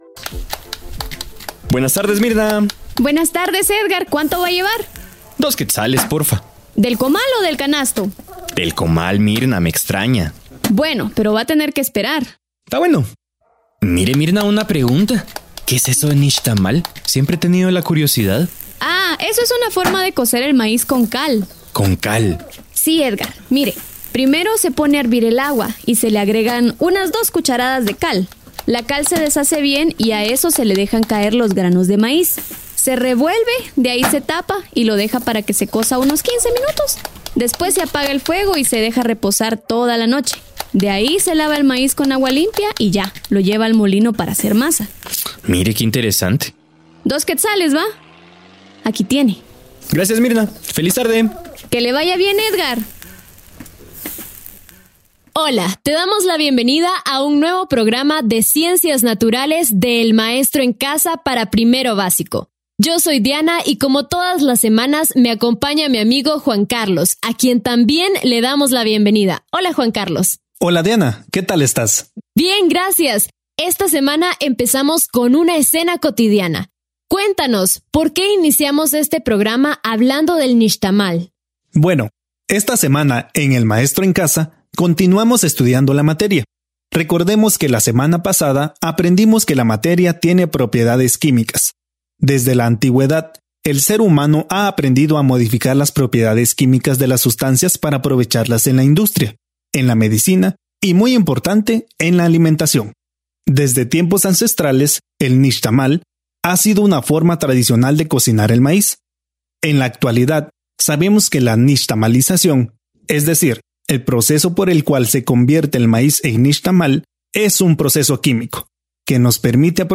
Desarrollé contenido para las clases radiales para «El Maestro en casa» y otros proyectos. Este es un extracto de una clase de Ciencias Naturales para Primero Básico